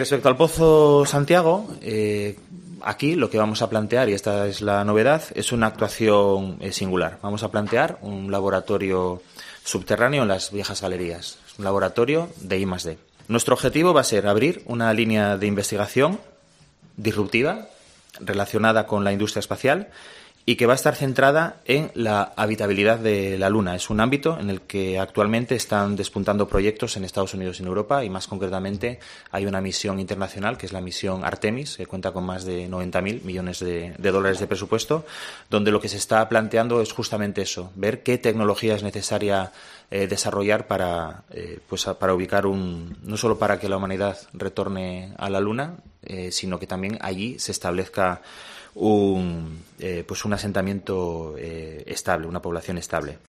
El consejero de Ciencia, Borja Sánchez, explica cómo será el laboratorio del pozo Santiago